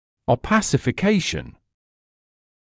[əu’pæsɪfɪ’keɪʃn][оу’пэсифи’кейшн]помутнение; контрастирование